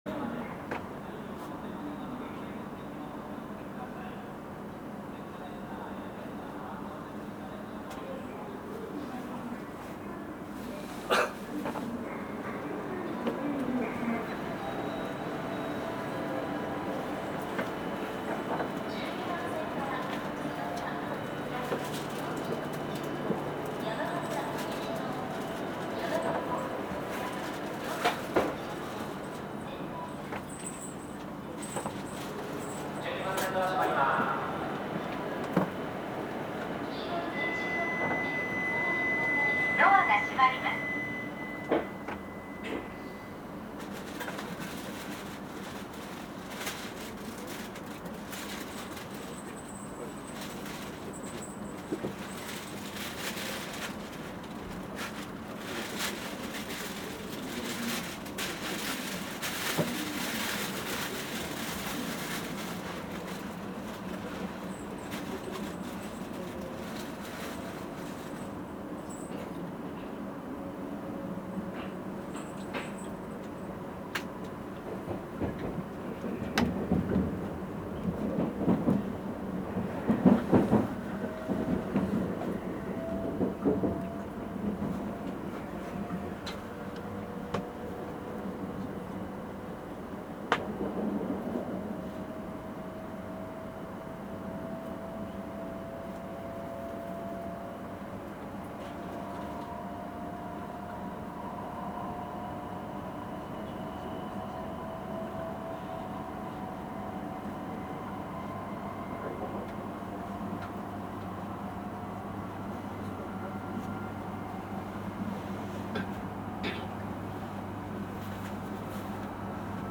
写真と走行音でE8系を紹介するページです。
走行音